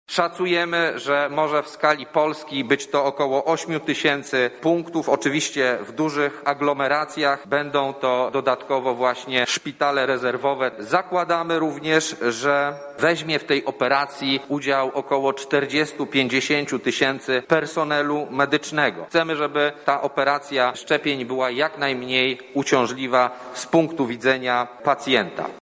Naszym celem jest stworzenie punktów, w których można szczepić się w każdej gminie- mówi minister Michał Dworczak: